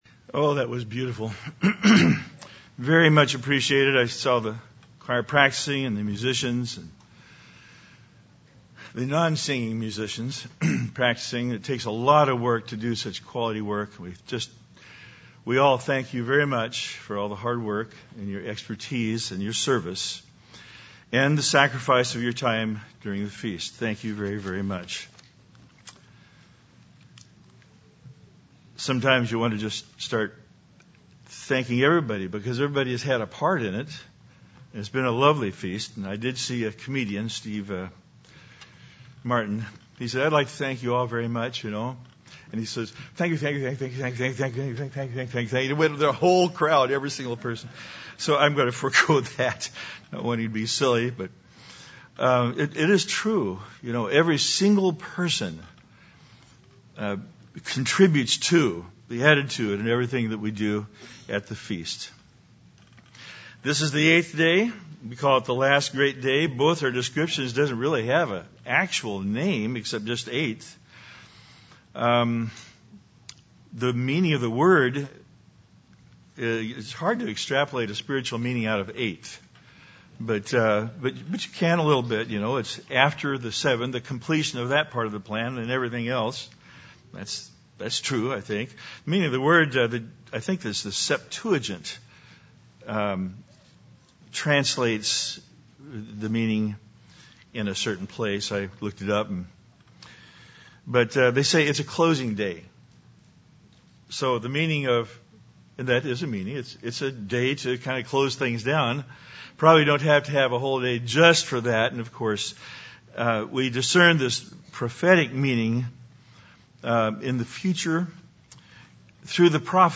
This sermon was given at the Branson, Missouri 2017 Feast site.